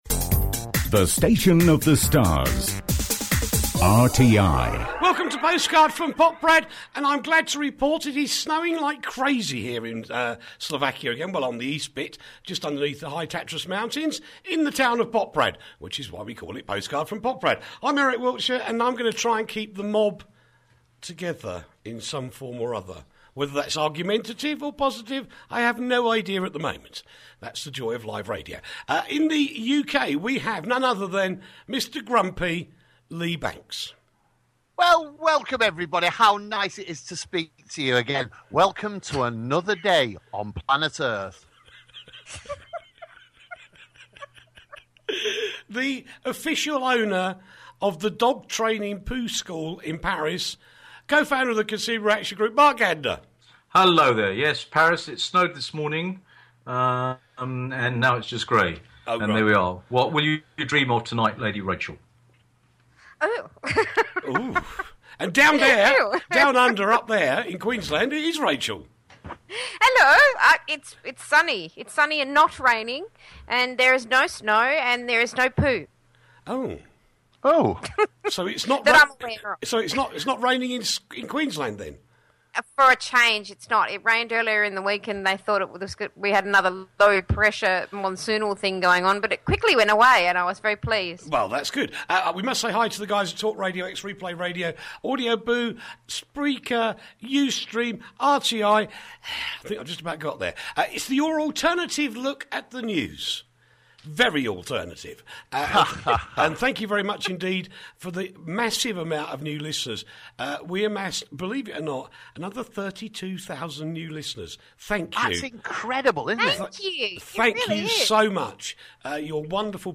Everything from Tech to Tabloid News.